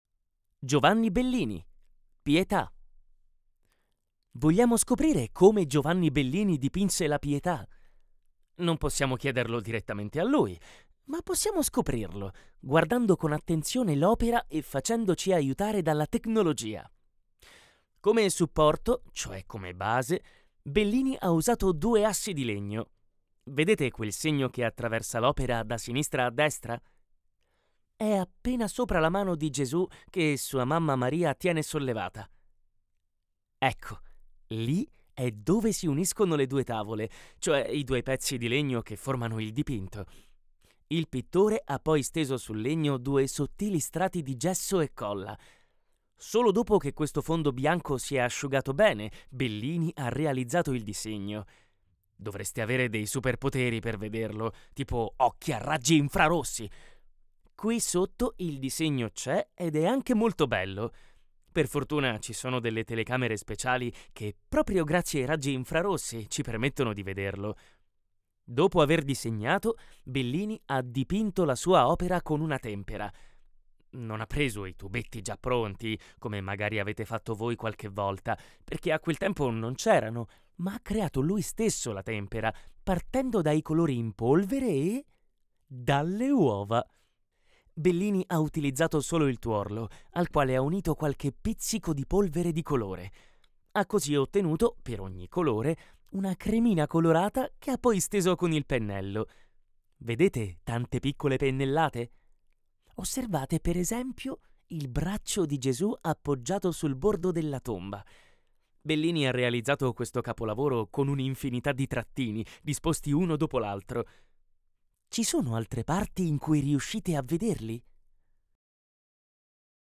Human Audio